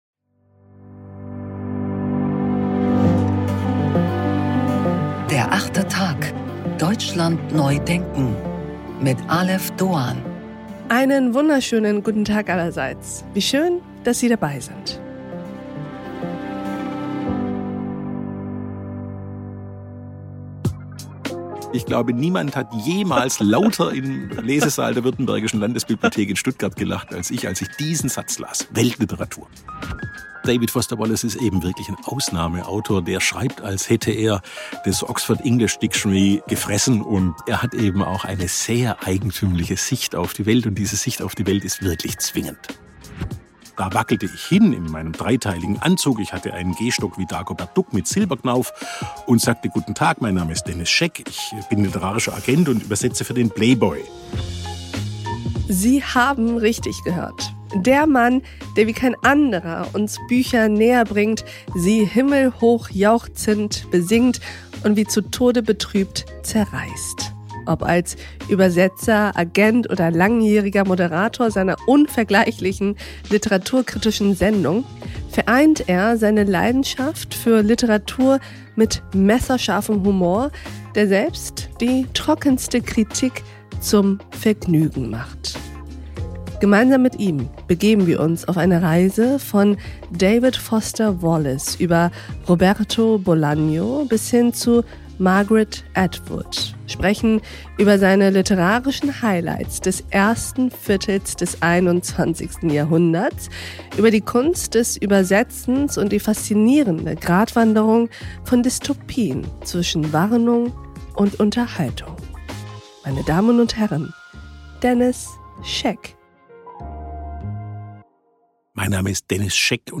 Literaturkritiker Denis Scheck über die zehn Bücher des 21. Jahrhunderts.